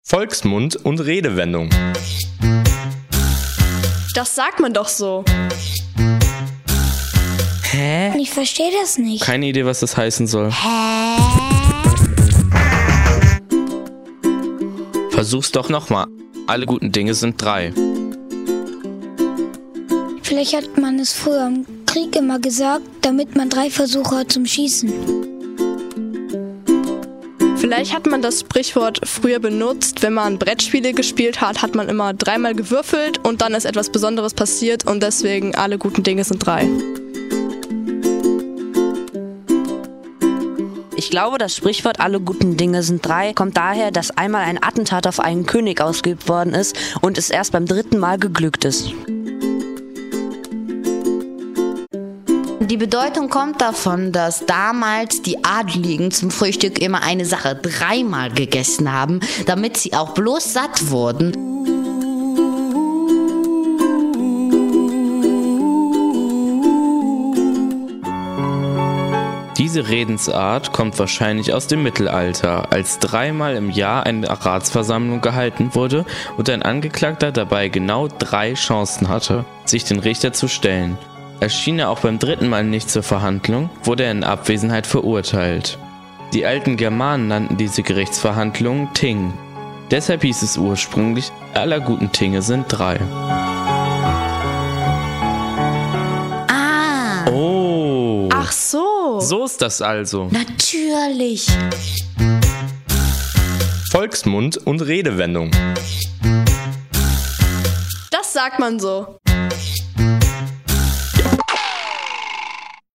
Unsere außerordentlich neugierigen EXLEX Radio Kids sind solchen Fällen auf den Grund gegangen.